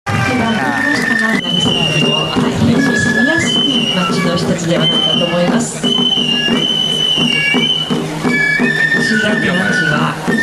♪ 長崎しゃぎり